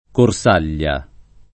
Corsaglia [ kor S# l’l’a ]